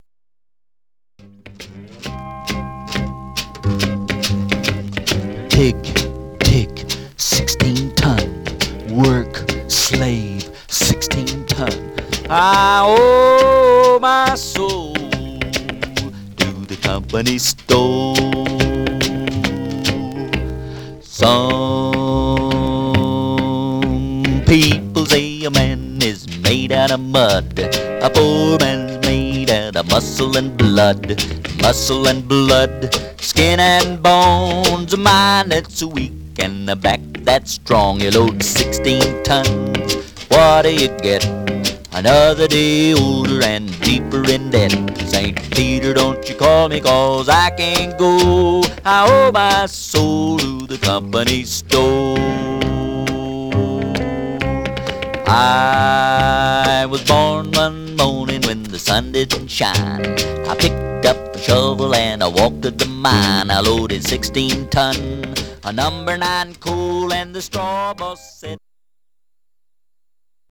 Stereo/mono Mono
Country